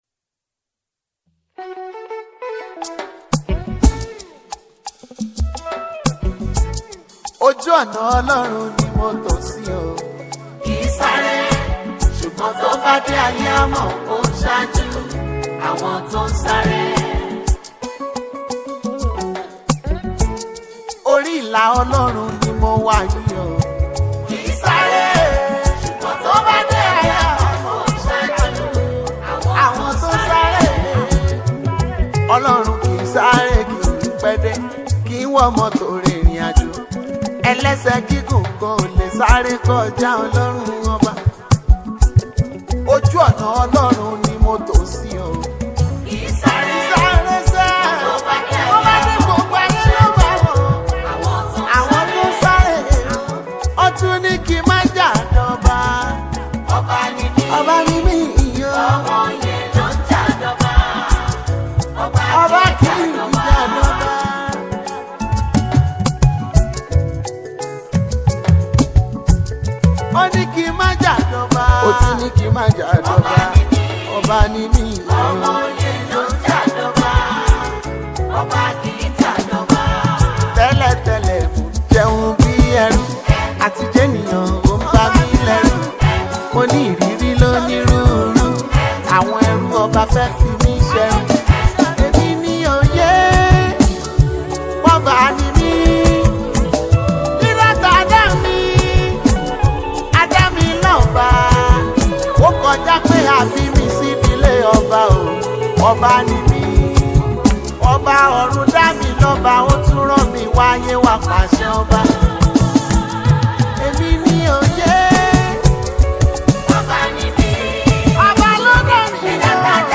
This most resent sound of worship and reverence
spirit-upraising melody